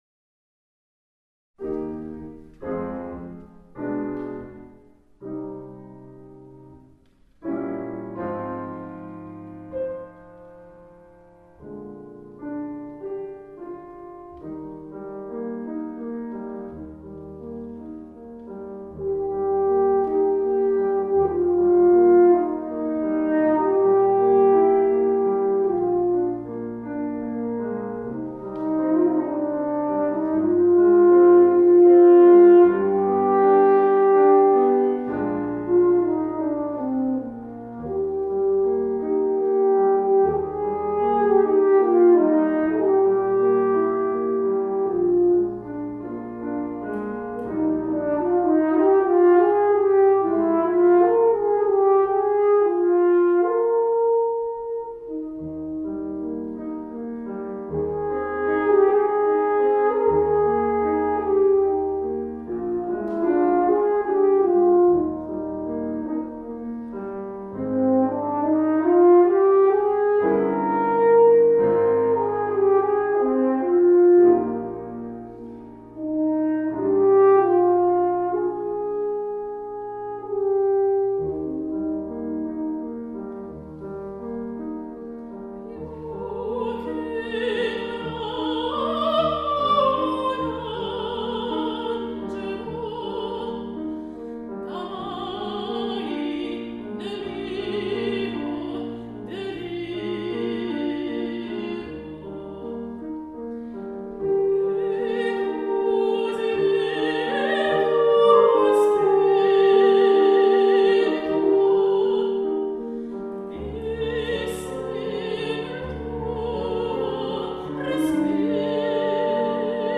Romanza per Tenore
con accompagnamento di Corno e Pianoforte